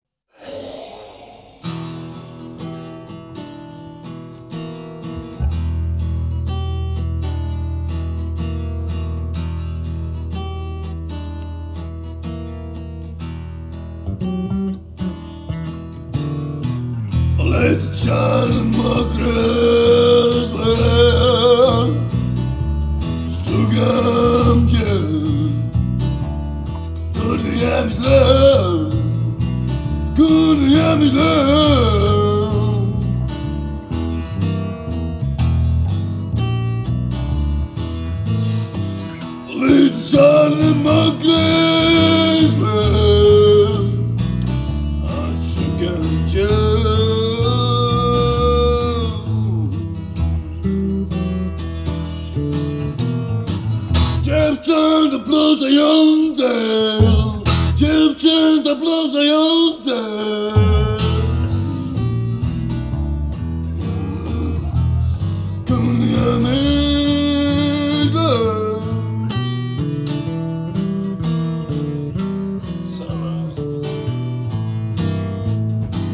z kręgu muzyki alternatywnej.
gitary i napięty emocją głos."
gitarka